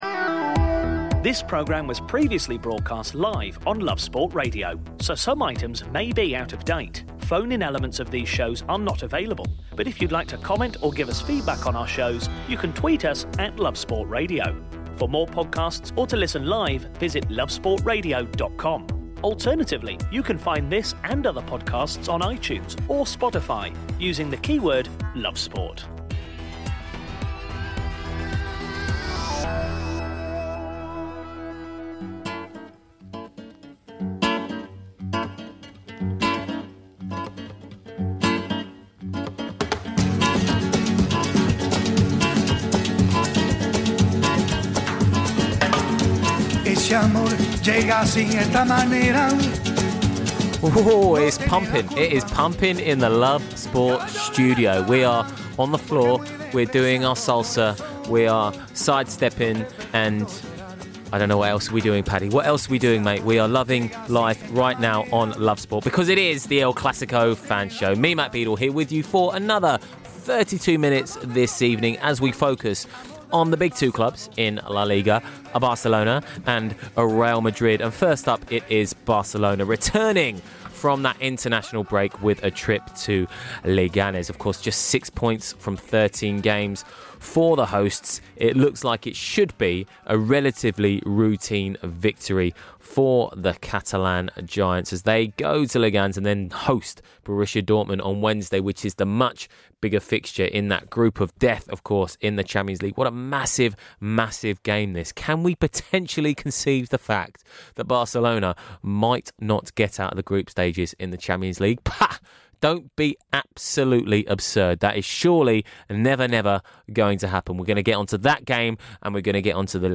Spanish Football journalists